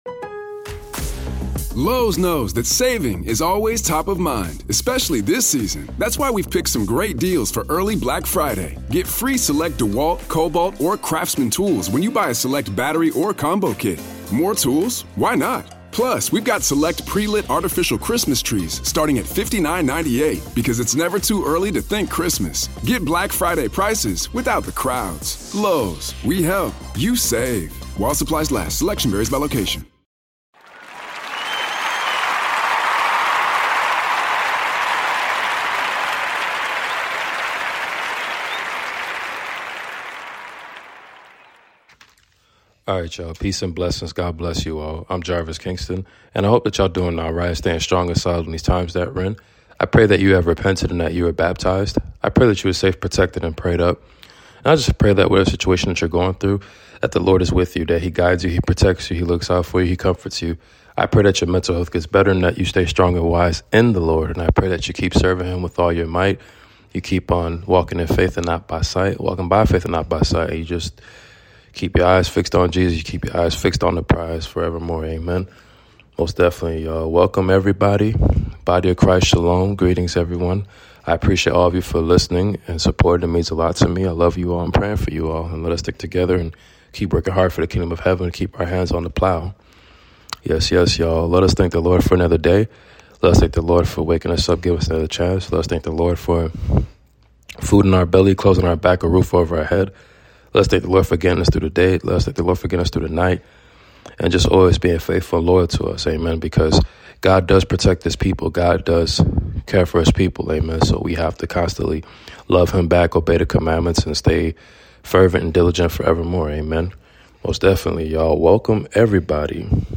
Book of Jude reading completion !